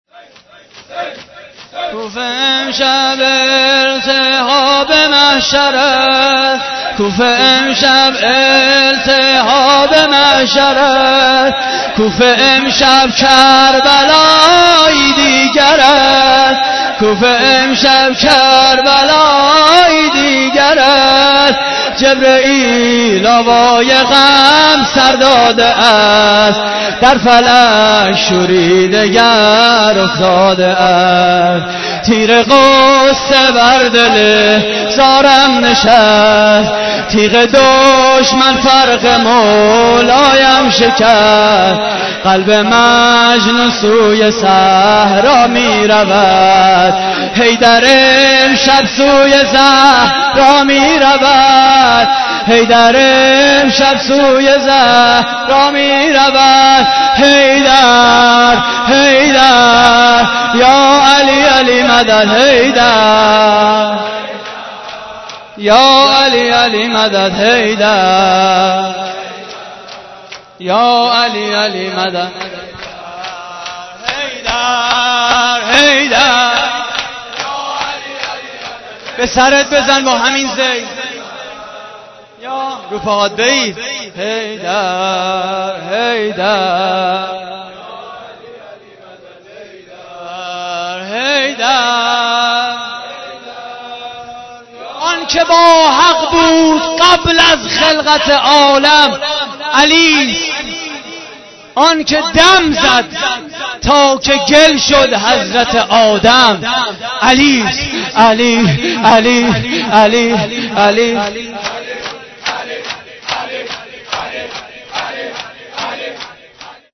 مداحی شب 21 رمضان (شهادت حضرت امیر ع) / هیئت کریم آل طاها (ع) - 30 مرداد 90
شور